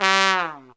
Index of /90_sSampleCDs/Zero-G - Phantom Horns/TRUMPET FX 3